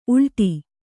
♪ ulṭi